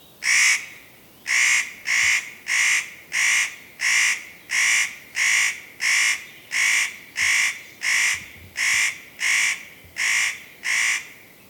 Art: Nøttekråke (Nucifraga caryocatactes)